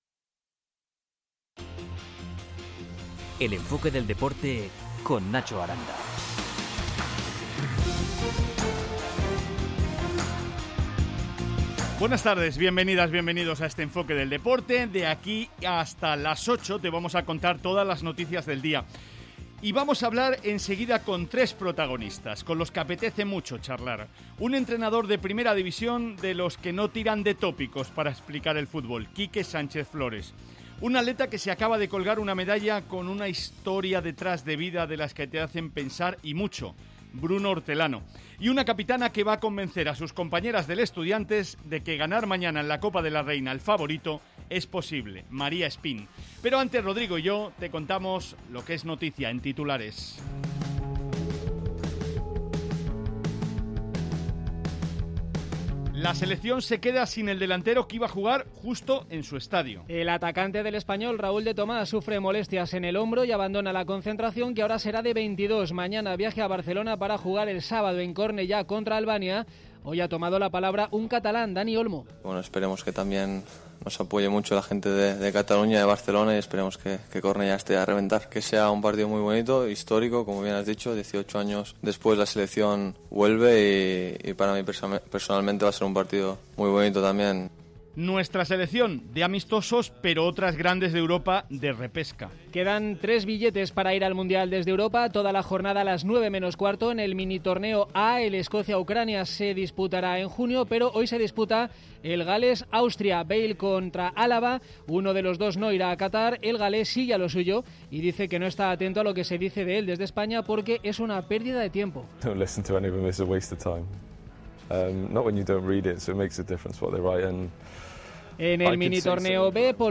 Una charla sin prisas con un entrenador imprescindible para conocer la historia reciente del Getafe y el Atlético de Madrid.